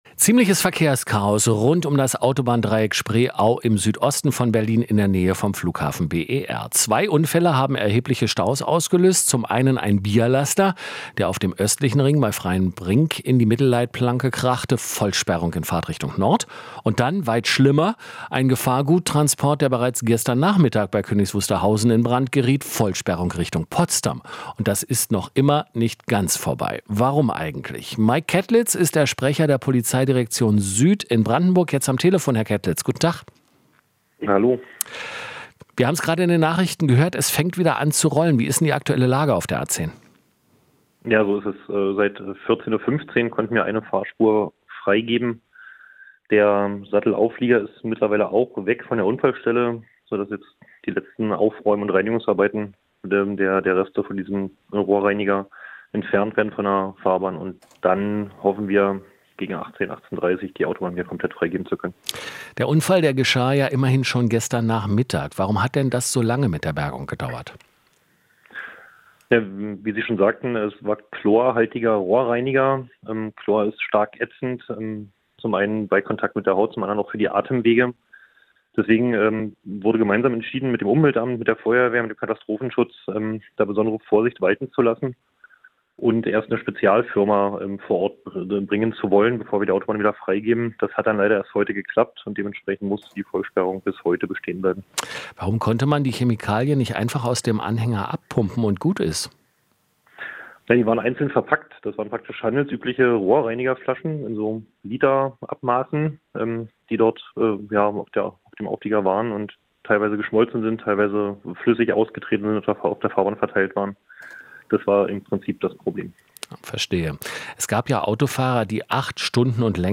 Inforadio Nachrichten, 03.10.2024, 23:00 Uhr - 03.10.2024